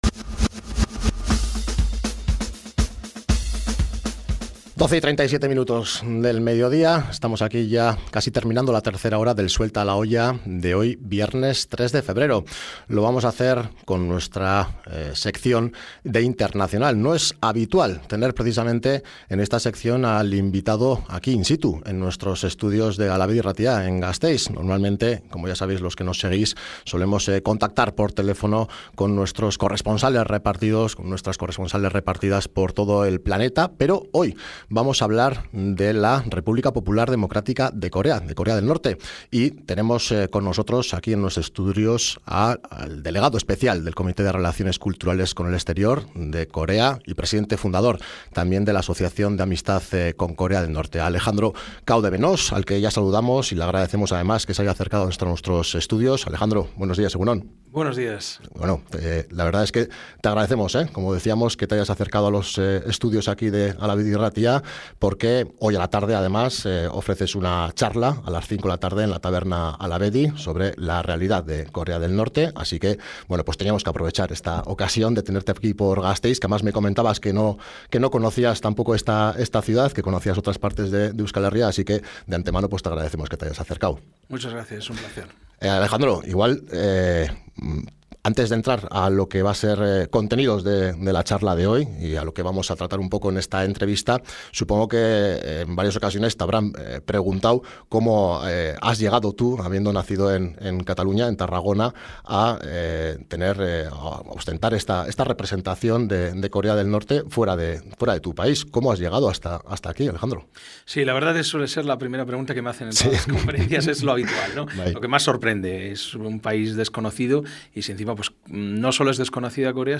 Entrevista con Alejandro Cao de Benós en Hala Bedi Irratia - KFA Euskal Herria
Ayer viernes 3 de febrero, la radio gasteiztarra Hala Bedi Irratia entrevistó en directo a Alejandro Cao de Benós, presidente y fundador de la KFA, y Delegado Especial del Comité de Relaciones Culturales con el exterior de la República Popular Democrática de Corea.